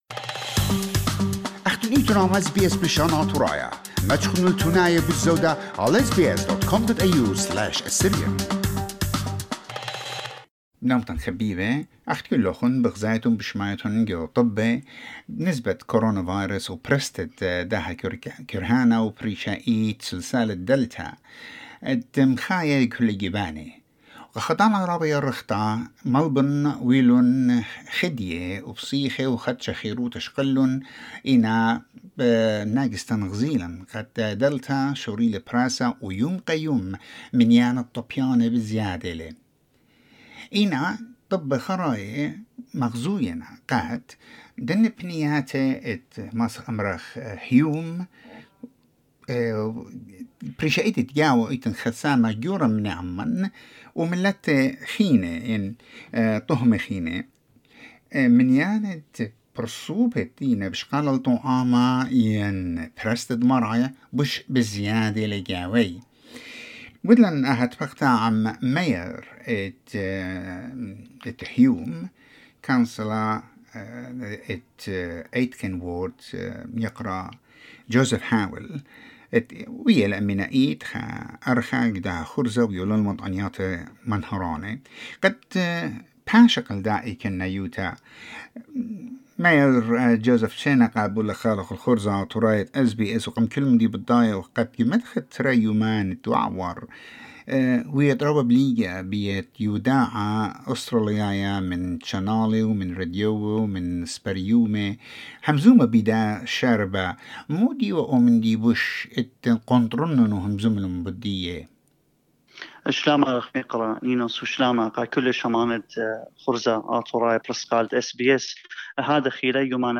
Latest figures from Melbourne show Hume City has a large percentage of infections and low vaccination rate. SBS Assyrian spoke to Mayor of Hume CR Joseph Haweil who urged the people of his area to minimise their interaction outside their immediate household and start vaccinating, for their own safety and health.